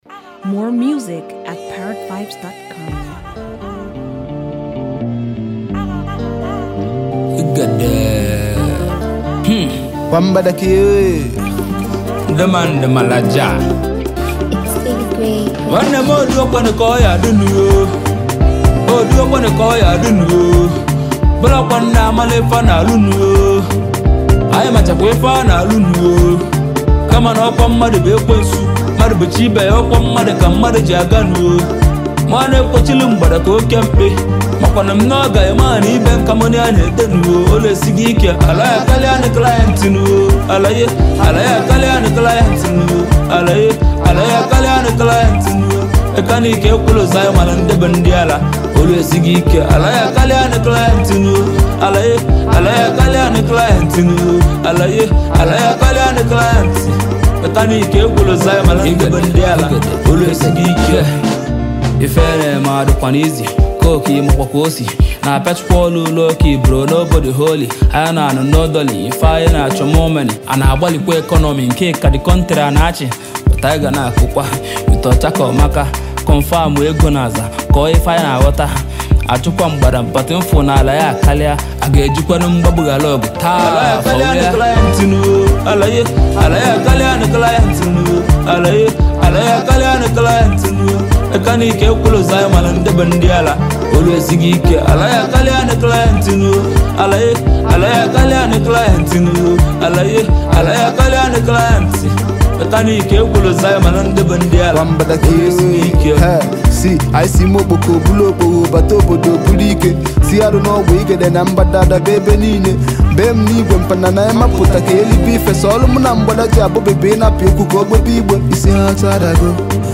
Highly skilled Nigerian recording artist and rapper